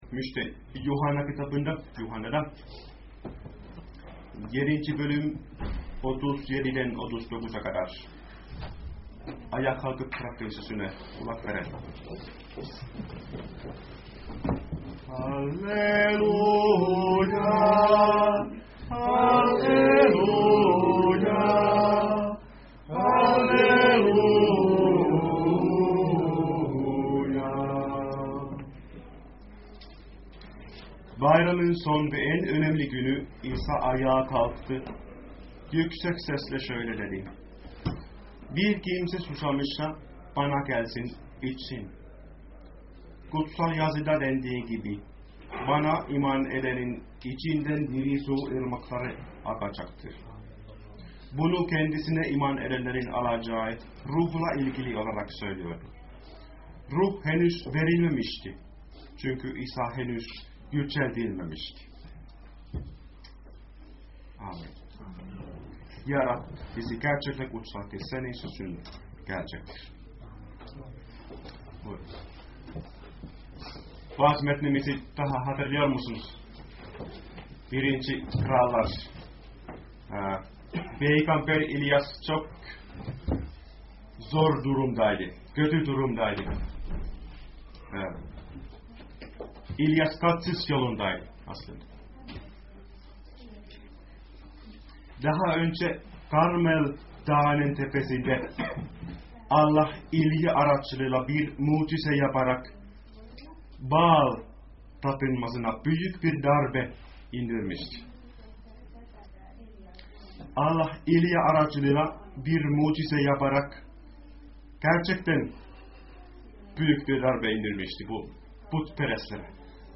2014 yılı: Yeni Antaşma’dan vaazlar